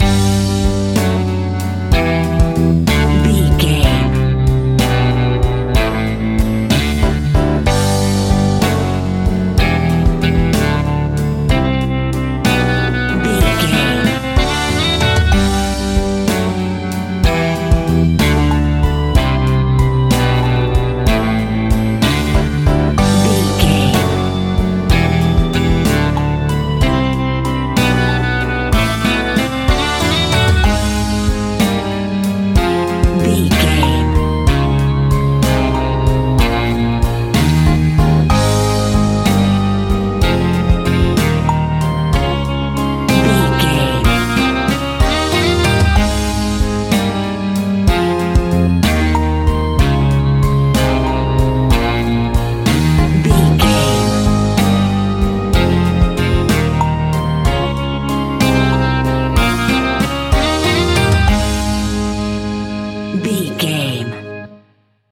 Uplifting
Ionian/Major
A♭
pop rock
indie pop
fun
energetic
acoustic guitars
drums
bass guitar
electric guitar
piano
organ